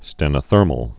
(stĕnə-thûrməl) also sten·o·ther·mic (-mĭk) or sten·o·ther·mous (-məs)